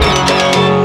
18 Floating In Credit Guitar 2.wav